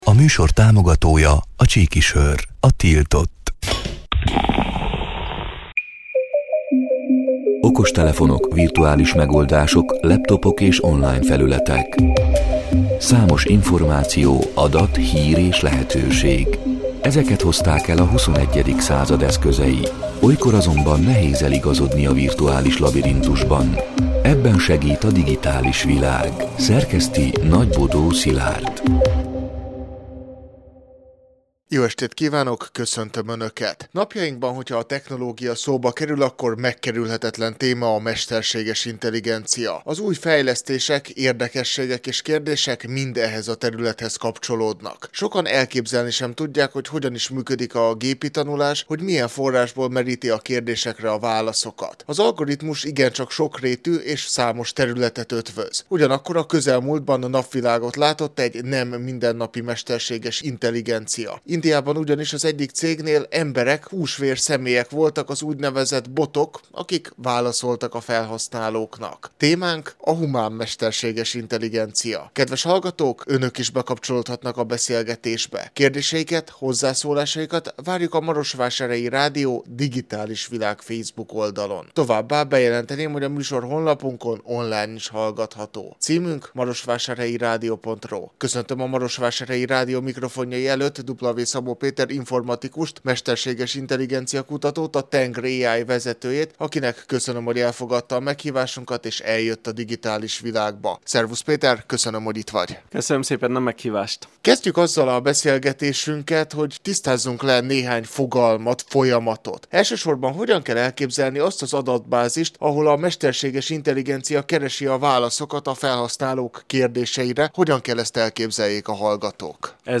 A Marosvásárhelyi Rádió Digitális Világ (elhangzott: 2025. június 17-én, kedden este nyolc órától) c. műsorának hanganyaga: